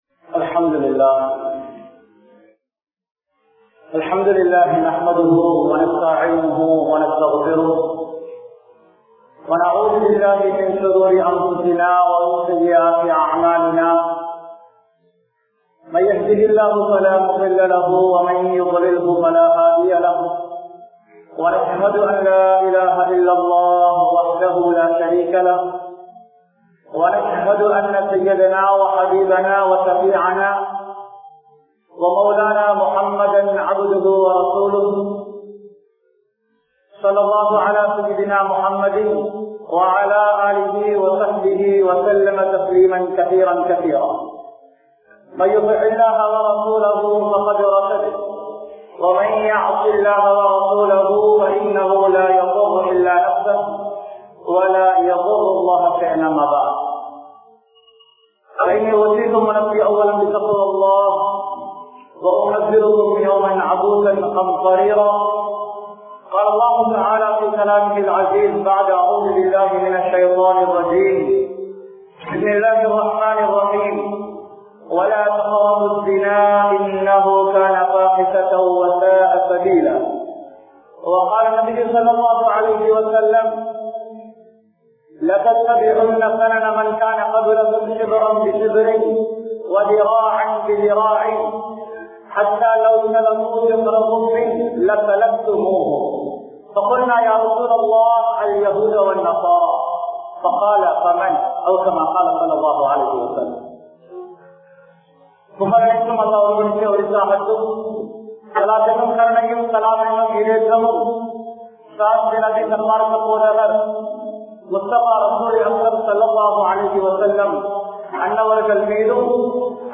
Kaathalar Thinamum Inraya Mulseemkalum(காதலர் தினமும் இன்றைய முஸ்லீம்களும்) | Audio Bayans | All Ceylon Muslim Youth Community | Addalaichenai